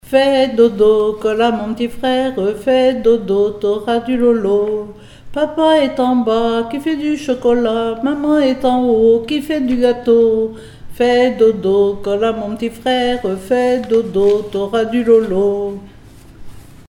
enfantine : berceuse
Comptines et formulettes enfantines
Pièce musicale inédite